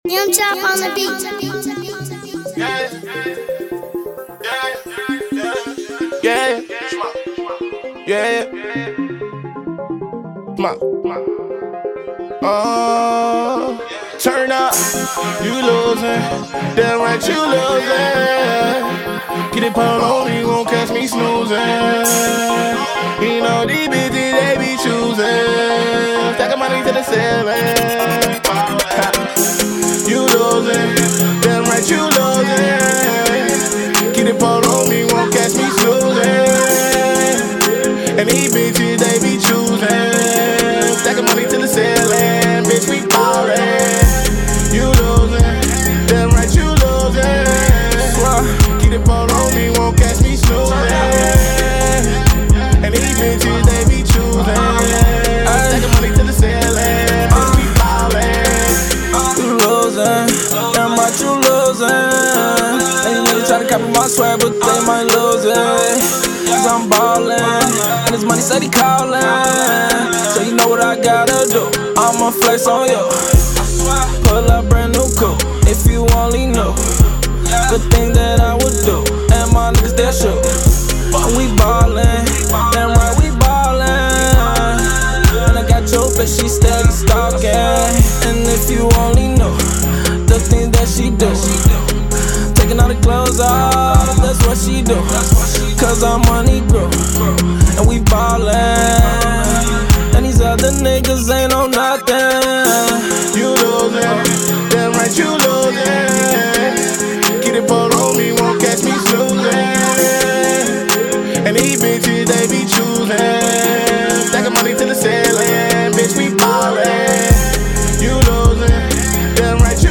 Chi town spittas